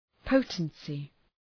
Προφορά
{‘pəʋtənsı}